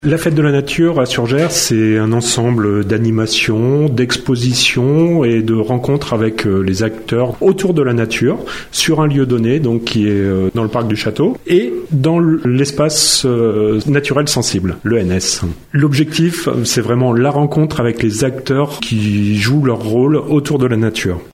La Ville propose ce samedi de découvrir ou redécouvrir la nature en plein centre-ville, à l’occasion d’expositions, de balades en calèche, d’ateliers créatifs et de visites guidées de l’Espace naturel sensible Bords de Gères. On écoute Stéphane Auger, adjoint au maire en charge de l’animation :